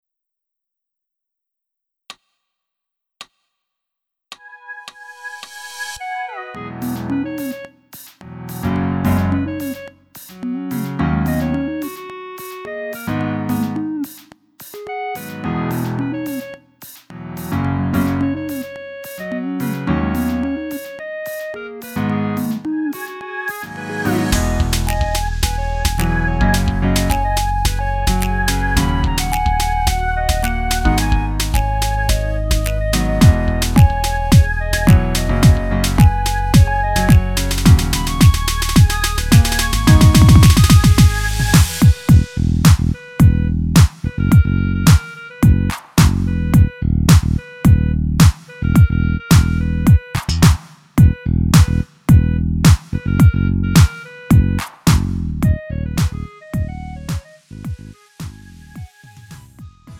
음정 -1키 3:08
장르 구분 Lite MR